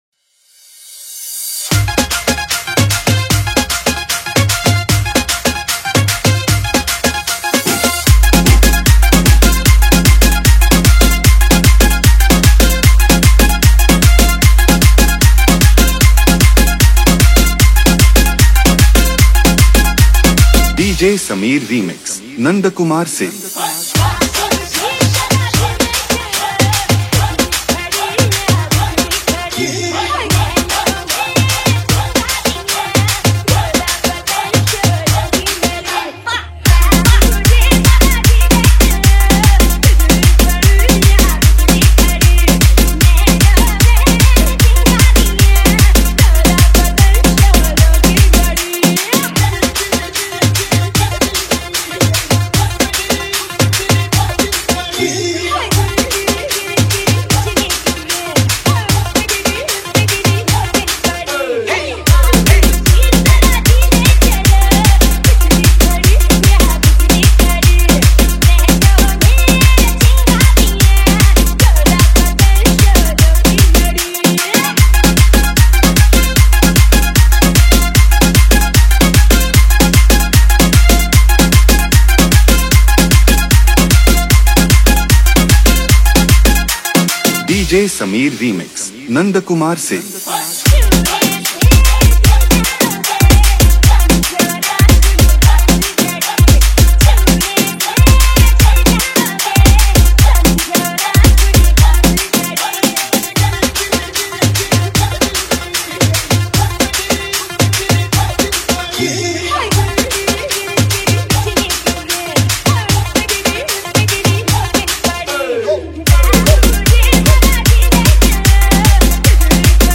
Category : Hindi Remix Song